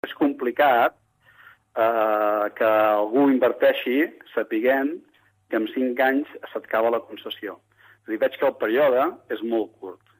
El portaveu del PSC a l’Ajuntament, Josep Coll, critica aquesta decisió ja que, segons ell, aquesta és la raó per la qual no es van adjudicar totes les parades.